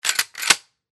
Звук выстрела дробовика в смс